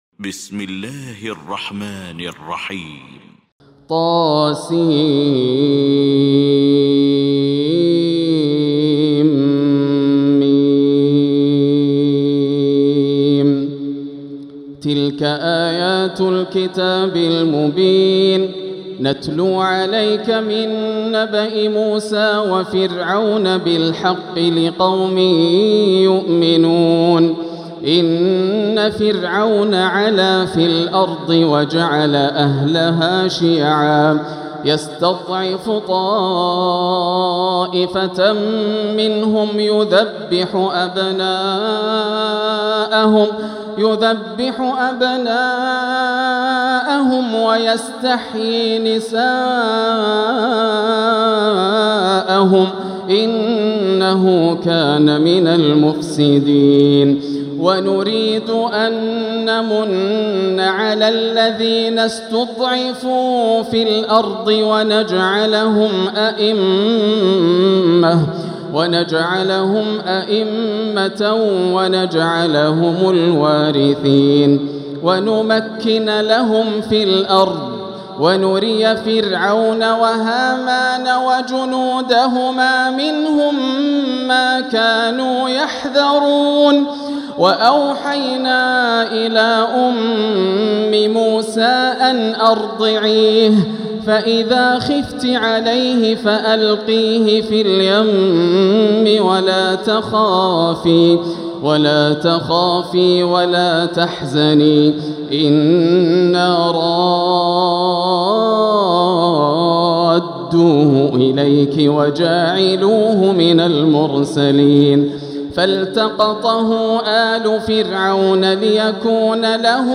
سورة القصص Surat Al-Qasas > مصحف تراويح الحرم المكي عام 1446هـ > المصحف - تلاوات الحرمين